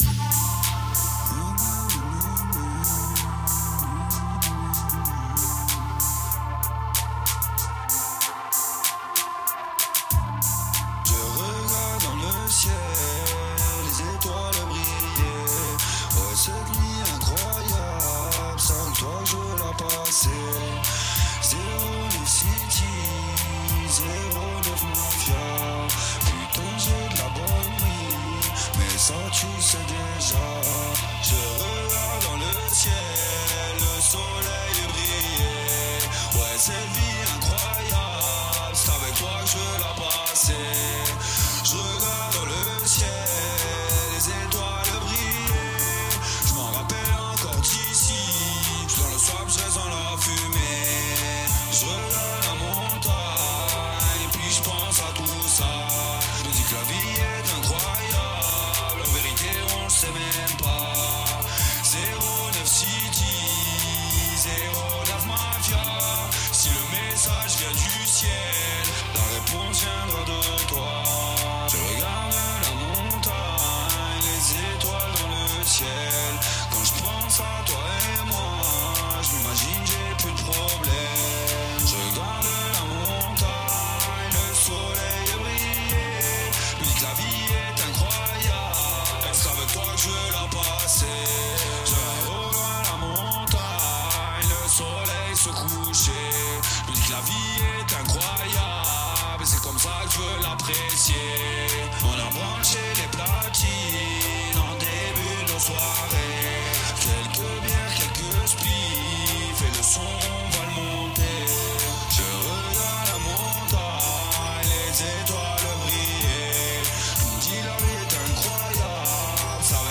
Selecta danchalle Raggamuffin